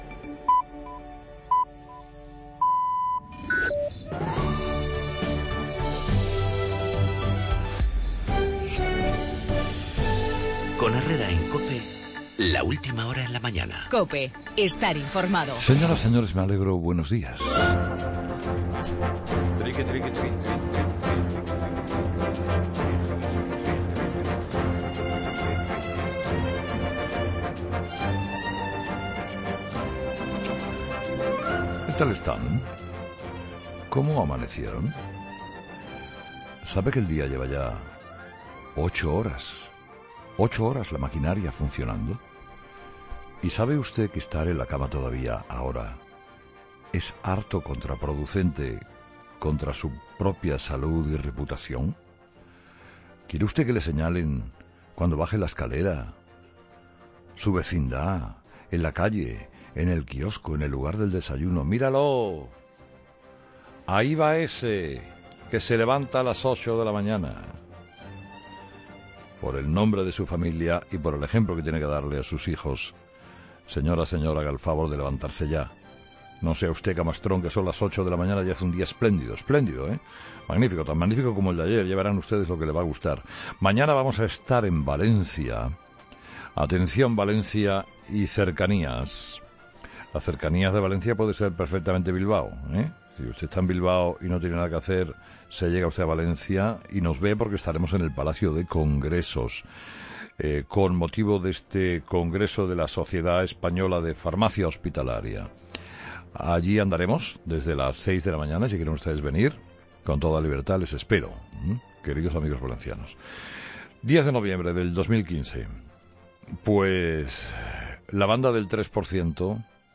La jornada "histórica" de este lunes en el Parlamento de Cataluña, en el editorial de Carlos Herrera a las 8.